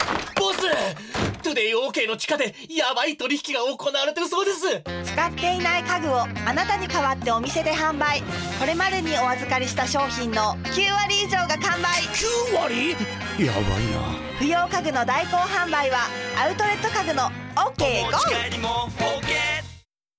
ラジオCM > 2016年 TODAY O!K 代行販売/地下取引ラジオCM